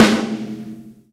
snare 1.wav